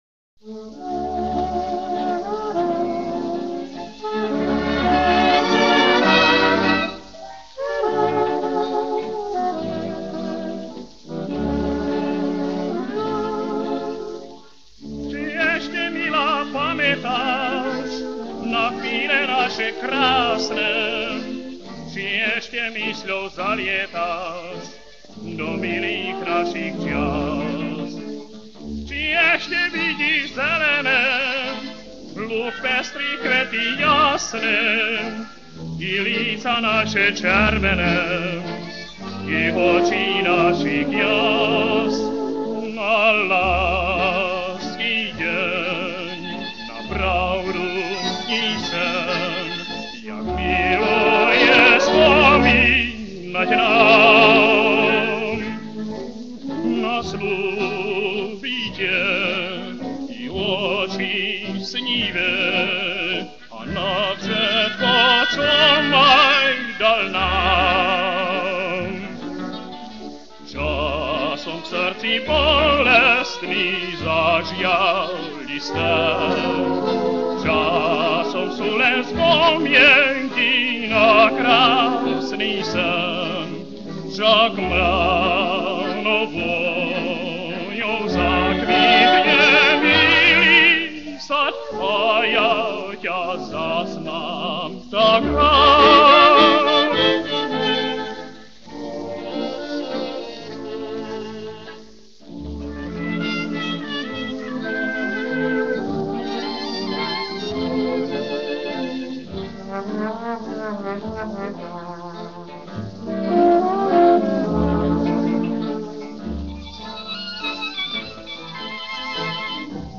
Dátum a miesto nahrávania: 28.4.1937, Studio Rokoska, Praha
pieseň a waltz